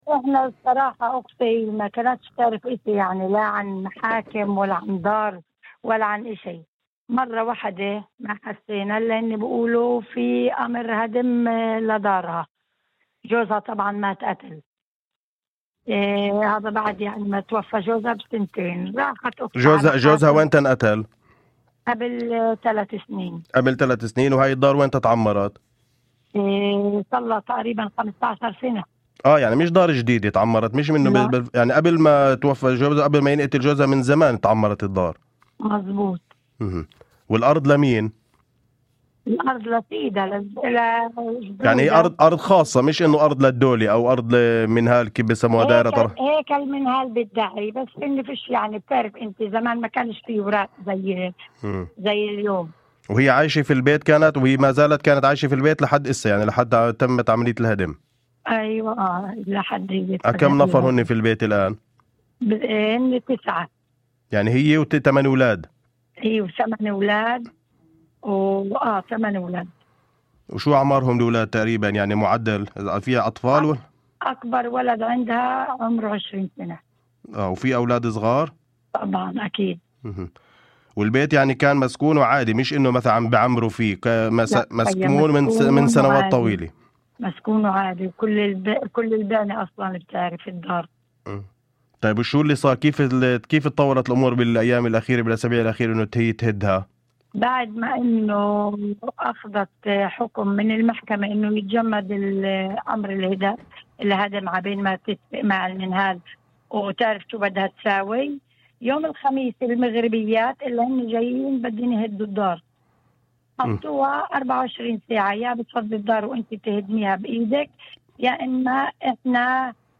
وأضافت في مداخلة ضمن برنامج "أول خبر" على إذاعة الشمس، أن زوج شقيقتها قُتل قبل نحو ثلاث سنوات، وأن المنزل شُيّد قبل مقتله بسنوات طويلة، وكان مأهولا بالسكان بشكل طبيعي، مضيفة أن شقيقتها كانت تعيش فيه مع أبنائها الثمانية، أكبرهم يبلغ من العمر عشرين عاما، وبينهم أطفال صغار.